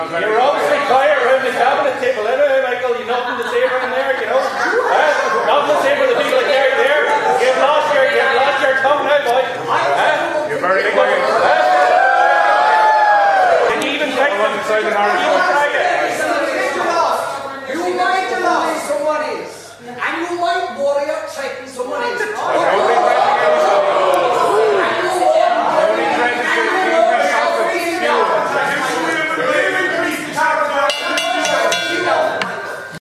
Yesterday’s Budget announcement has been the hot topic in the Dáil Chamber today with one brief outburst between Donegal Deputy Pearse Doherty and Minister Michael Healy Rae.
Deputy Doherty accused Minister of remaining silent at the cabinet table: